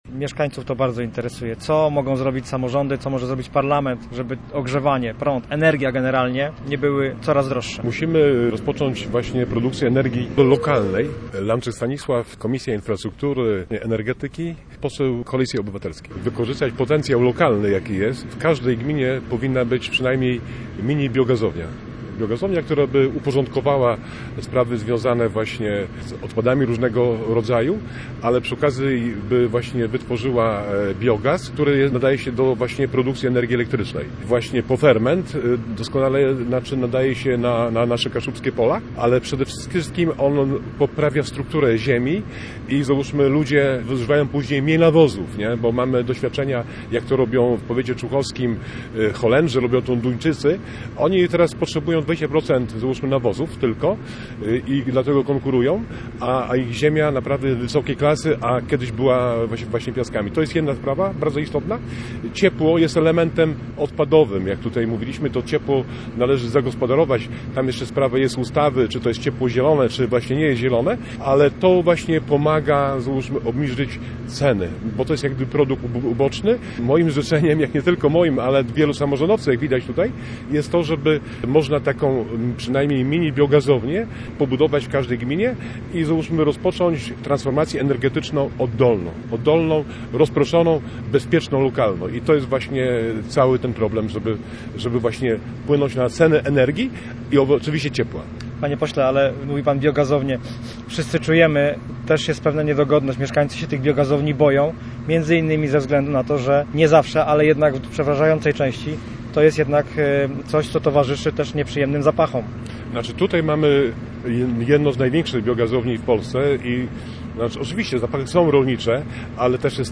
W Potęgowie koło Słupska rozmawiano dziś o tym, jak produkować ekologiczną energię i ograniczać koszty ponoszone przez mieszkańców. Posłuchaj materiału reportera Radia Gdańsk: https